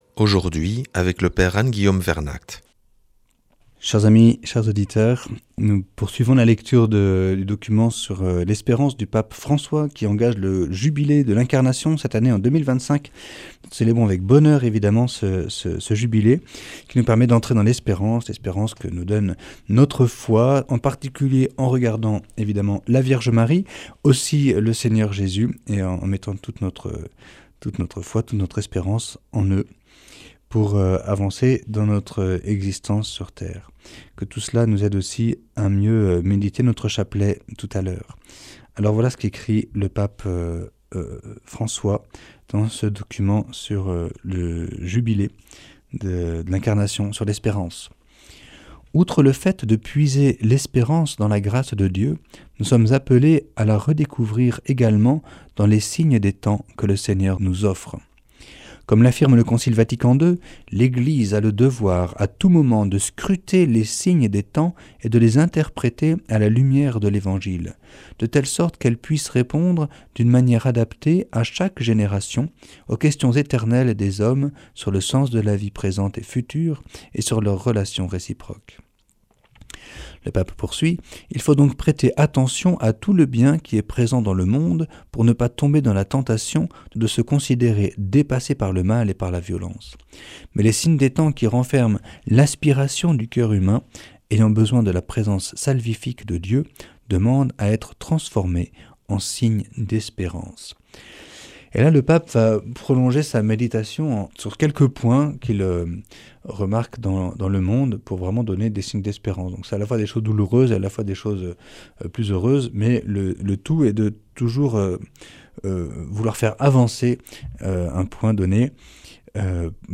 Enseignement Marial du 03 janv.